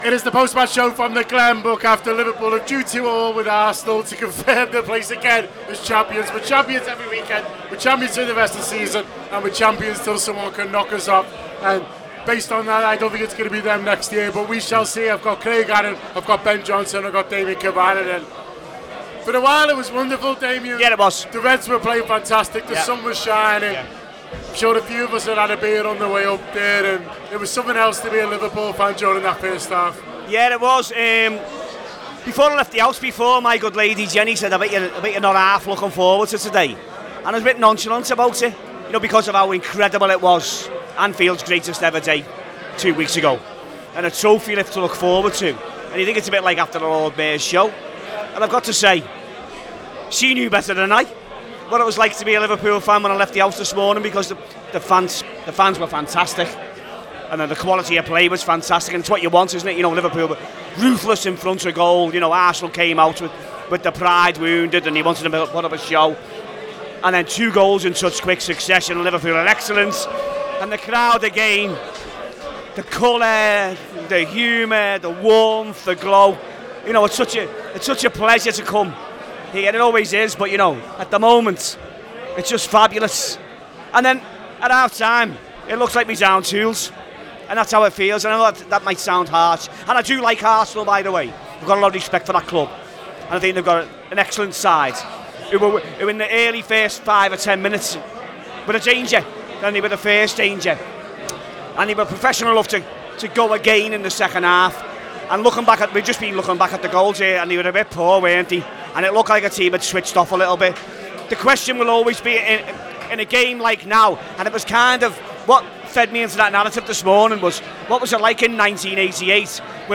Below is a clip from the show – subscribe to The Anfield Wrap for more on the 20 x Champions Of England